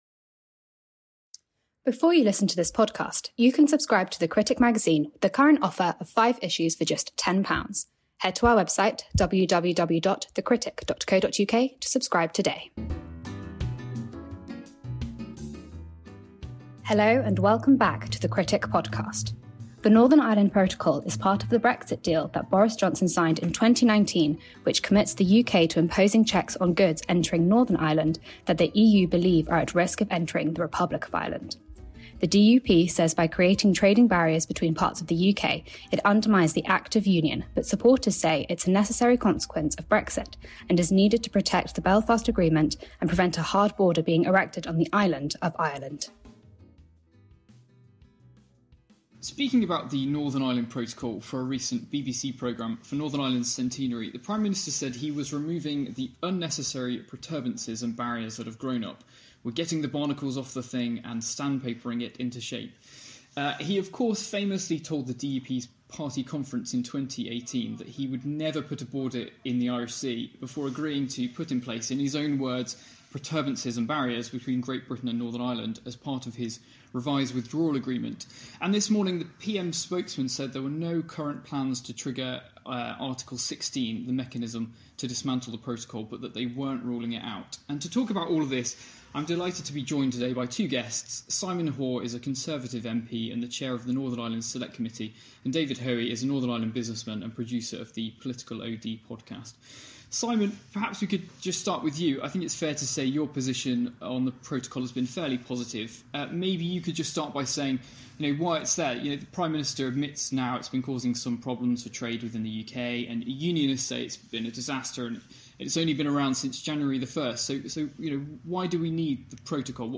debate the merits of the Northern Ireland Protocol